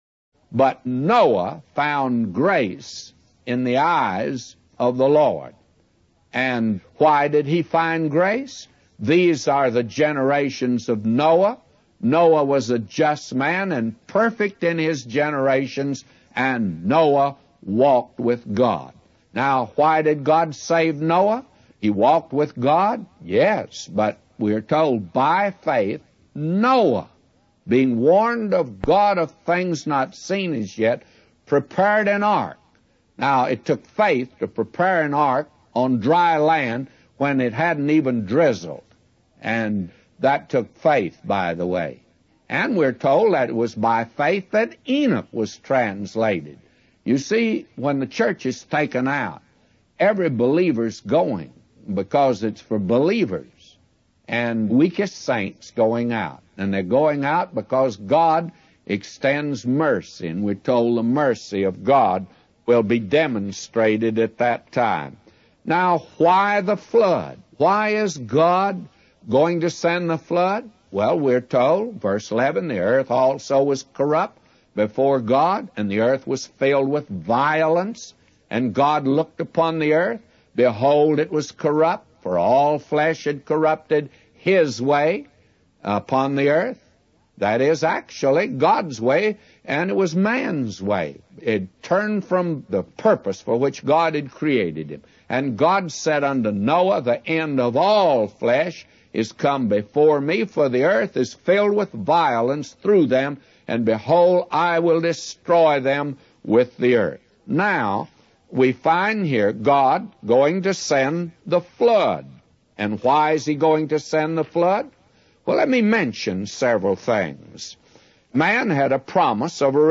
The sermon explains the story of Noah and the flood, highlighting the importance of faith, the consequences of rejecting God's way, and the demonstration of God's mercy.